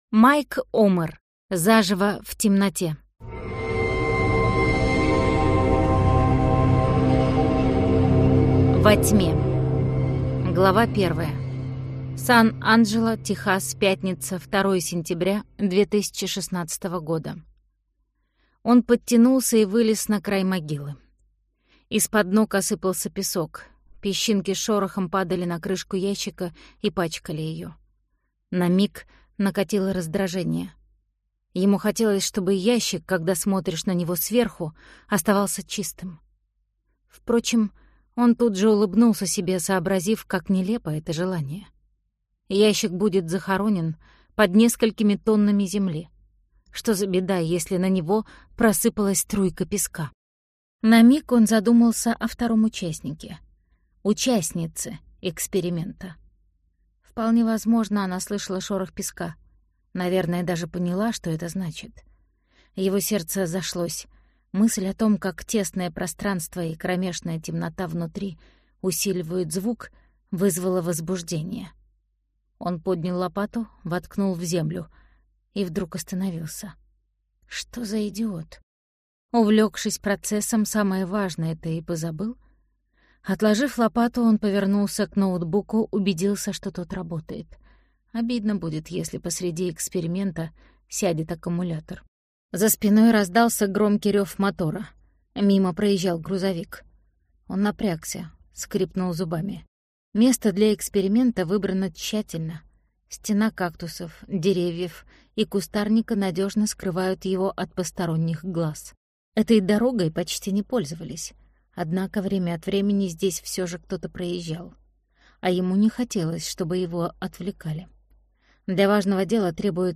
Аудиокнига Заживо в темноте - купить, скачать и слушать онлайн | КнигоПоиск